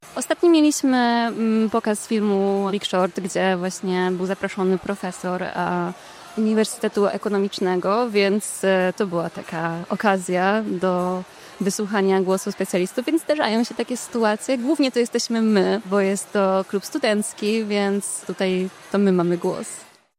W środę, 29 października, wybraliśmy się na miejsce, żeby dać głos przedstawicielom kół naukowych.